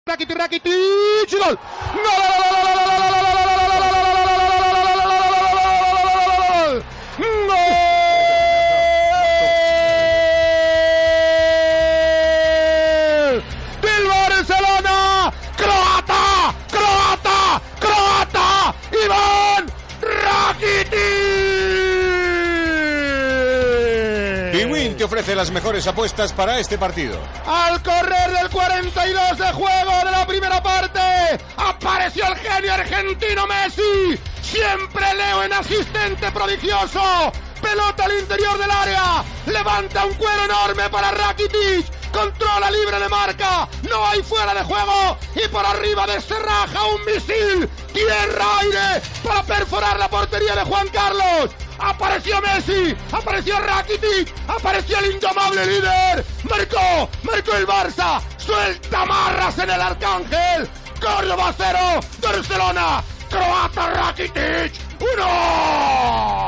Transmissió del partit de lliga de la primera divisió masculina de futbol entre el Córdoba i el Futbol Club Barcelona.
Narració del gol de Ivan Rakitić.
Esportiu